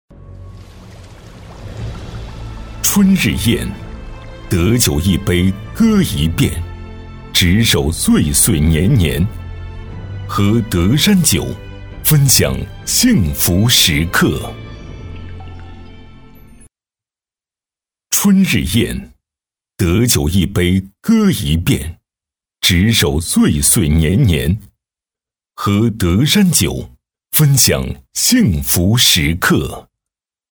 男国136-【广告 酒】德山酒
男136-大气主流 高端大气
男国136-【广告 酒】德山酒.mp3